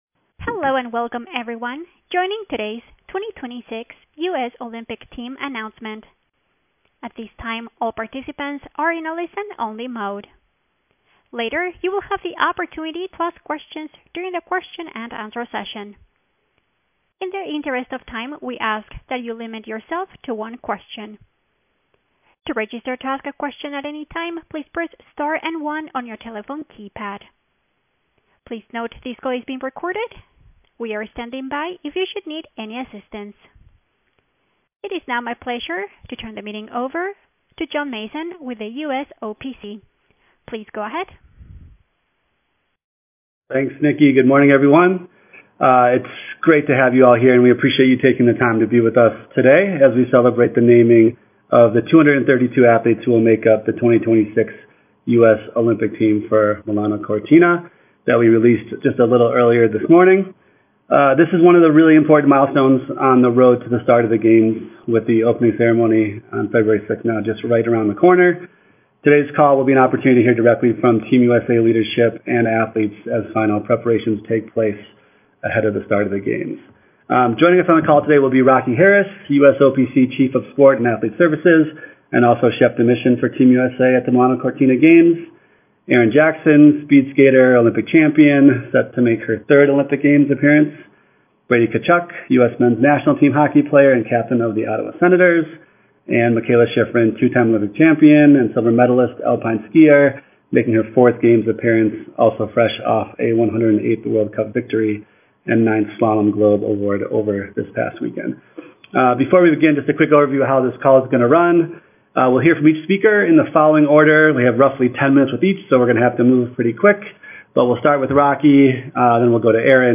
Below is the audio recording from the U.S. Olympic & Paralympic Committee press briefing on Monday, Jan. 26, following the 2026 U.S. Olympic Team announcement.